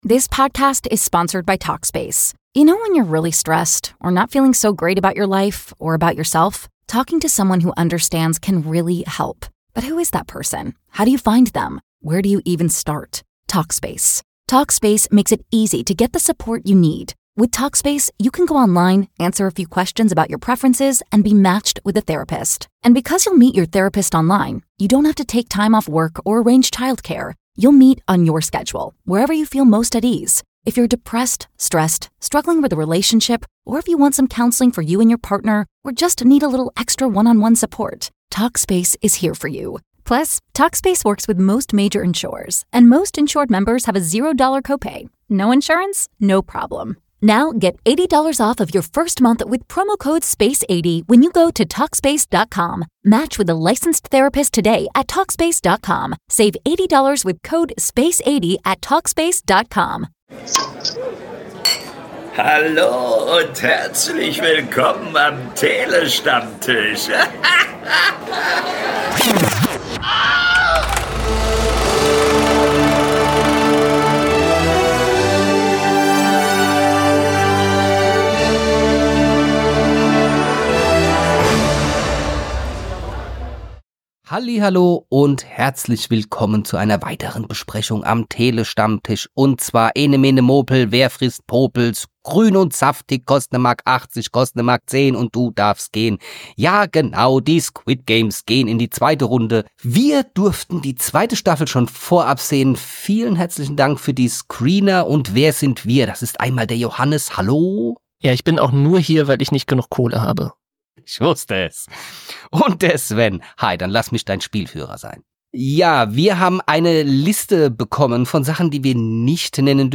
Wir liefern euch launige und knackige Filmkritiken, Analysen und Talks über Kino- und Streamingfilme und -serien - immer aktuell, informativ und mit der nötigen Prise Humor. Website | Youtube | PayPal | BuyMeACoffee Großer Dank und Gruß für das Einsprechen unseres Intros geht raus an Engelbert von Nordhausen - besser bekannt als die deutsche Synchronstimme Samuel L. Jackson!